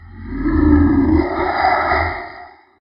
growl-5.ogg